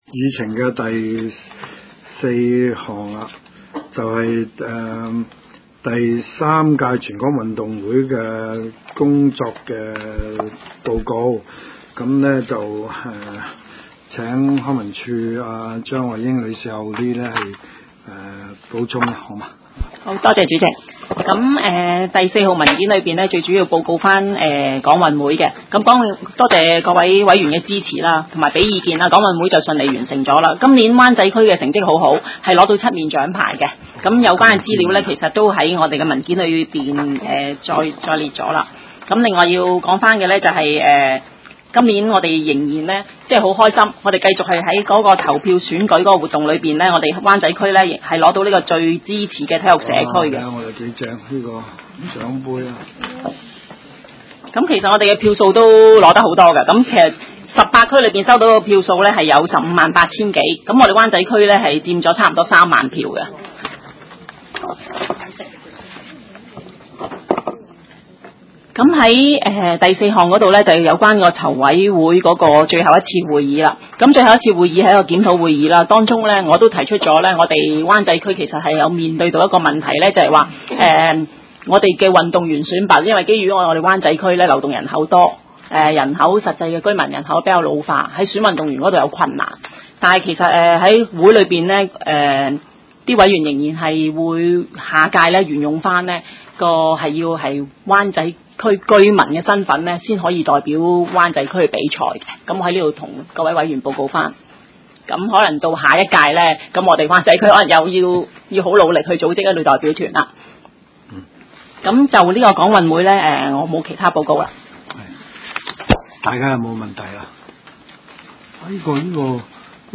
文化及康體事務委員會第二十三次會議
灣仔民政事務處區議會會議室